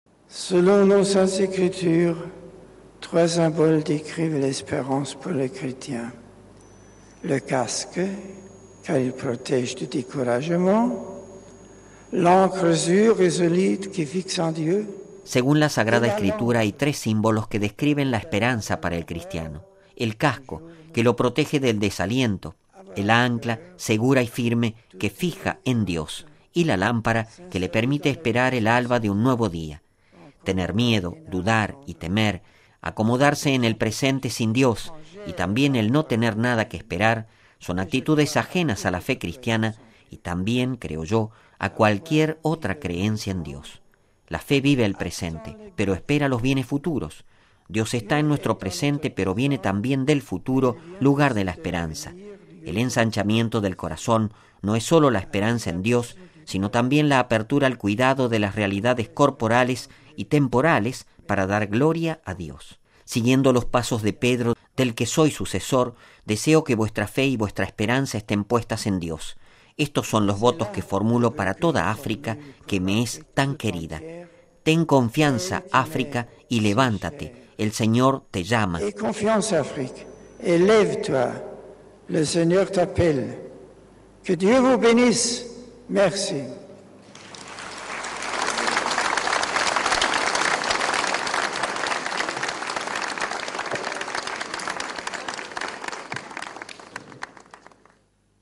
Escuchemos la última parte de la homilía del Papa pronunciada esta mañana ante las autoridades gubernamentales, el cuerpo diplomático y representantes de las principales religiones, en la Sede del Palacio Presidencial de Cotonou: